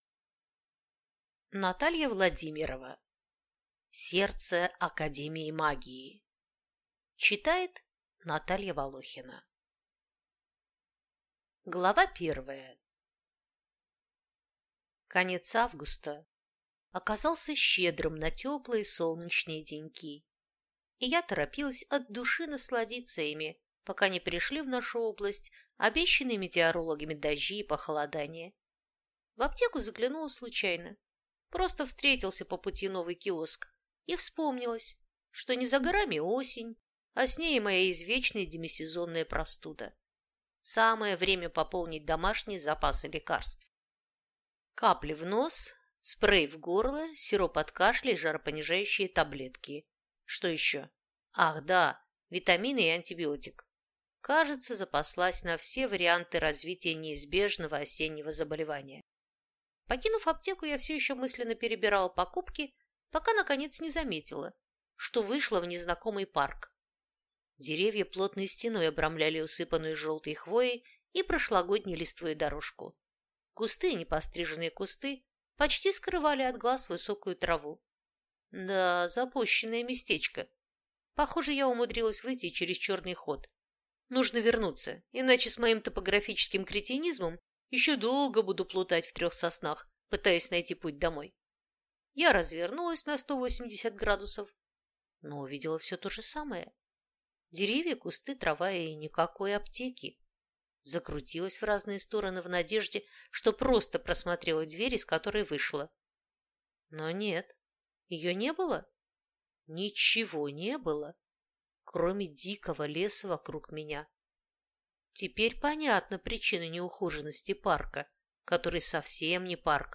Аудиокнига Сердце академии магии | Библиотека аудиокниг